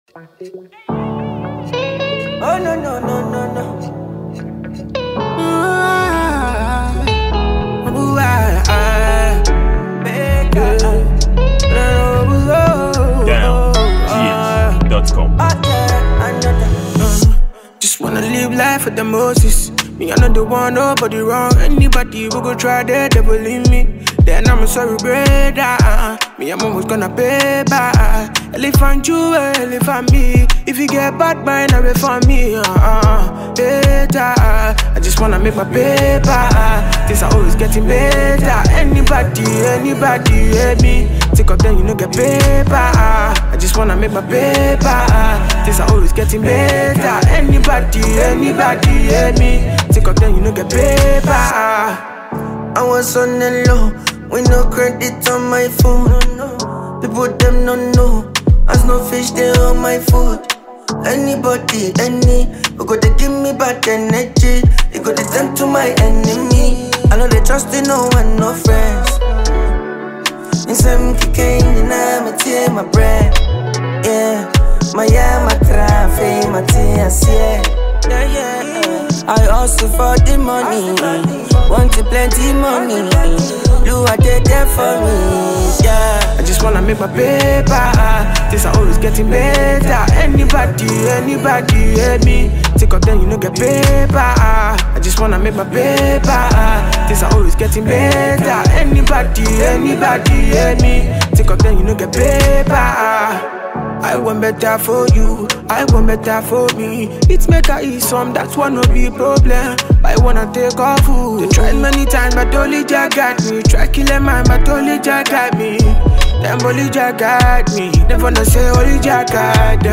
afrobeat song